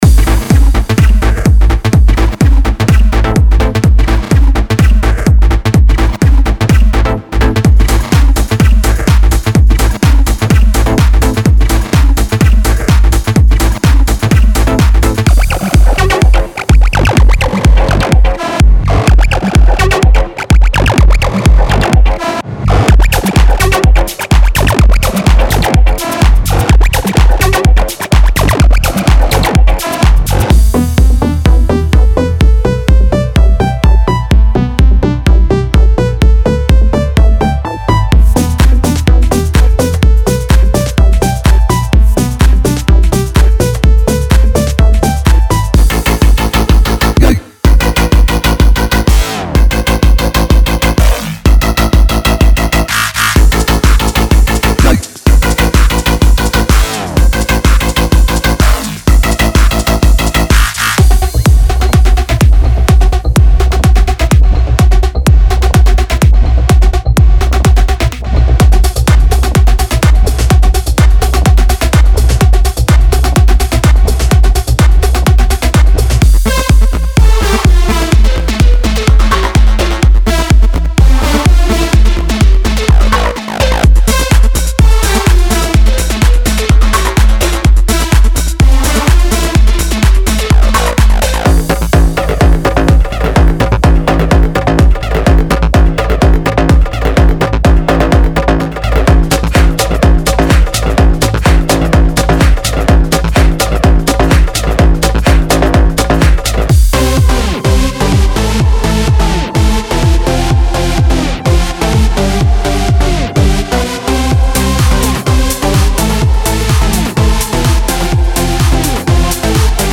Type: Midi Samples
Big Room Deep House Progressive House Tech House
including melodies, basslines, drums & so on.
⦁ 126 BPM